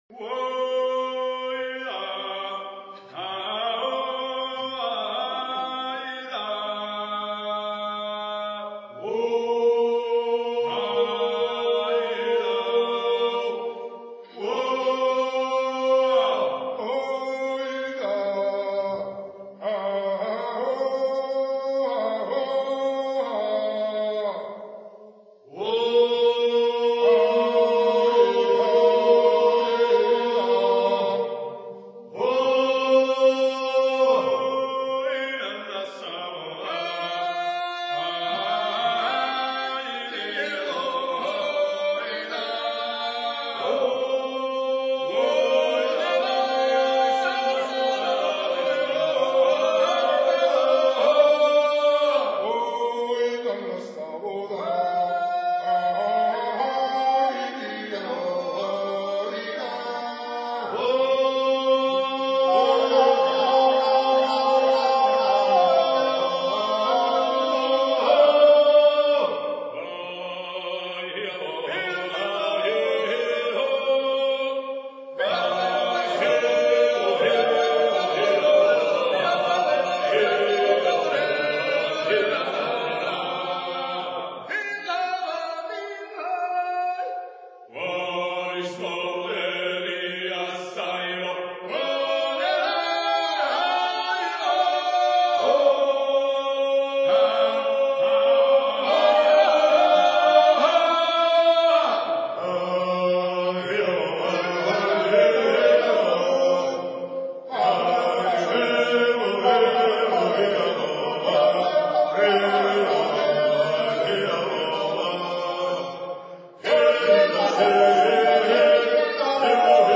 Work song of this type are only typical of Guria region. The song was sung while hoeing in the field.